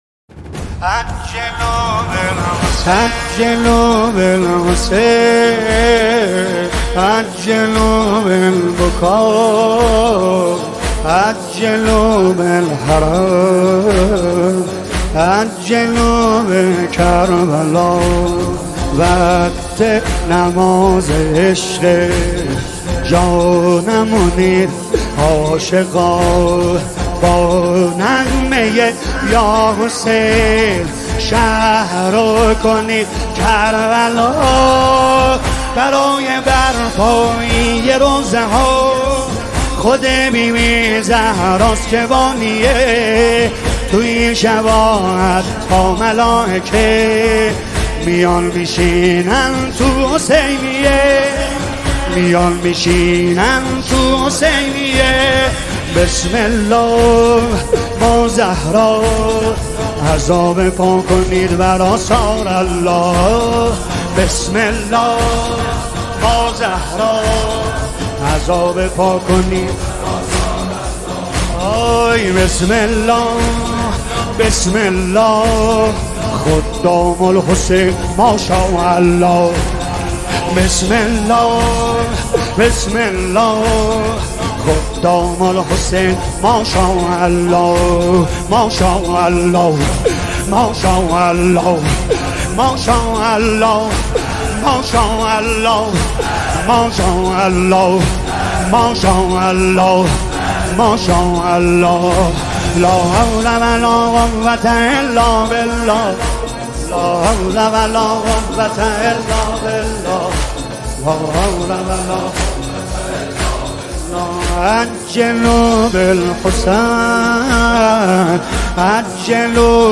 زیبا و دلنشین
با نوای مداح اهل بیت